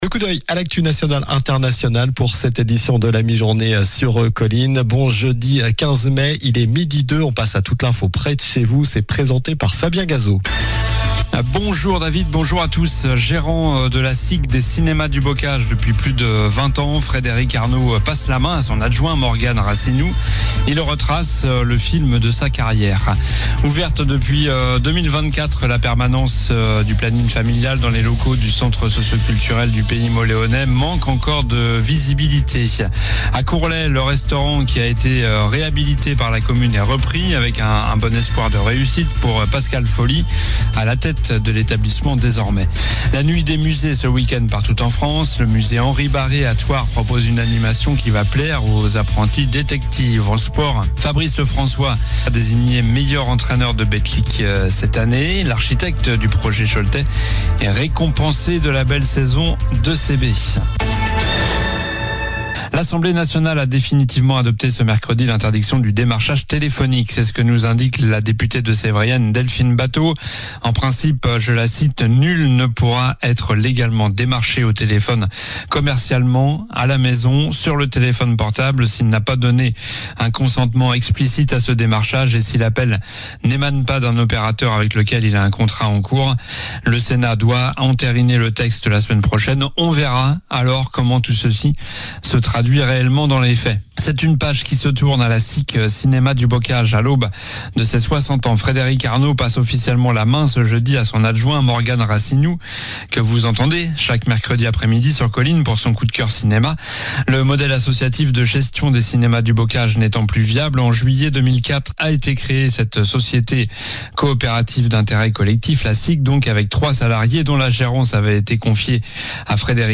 Journal du jeudi 15 mai (midi)